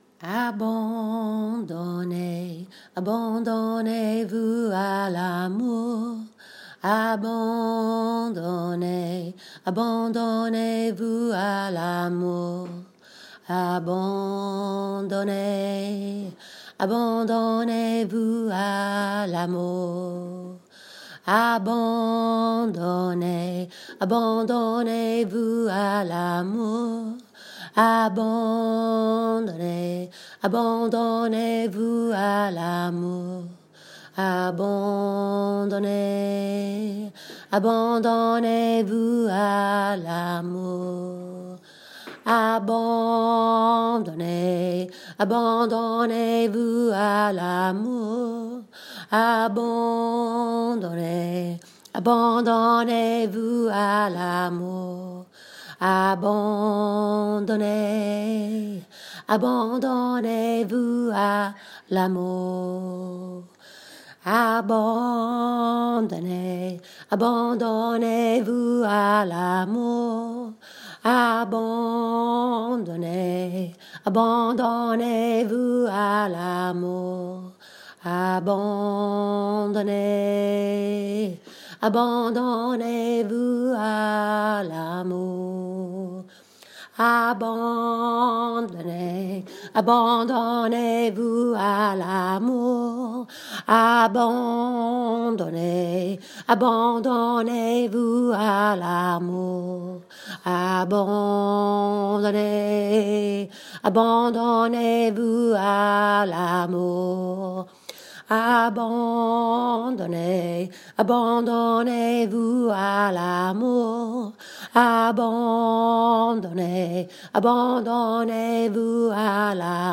Ceux d’entre vous qui se joignent à moi pour vocaliser et unir la voix de nos âmes sauront combien j’aime me brancher dans ce mantra.
Chantez-la de tout votre cœur et sentez sa puissance.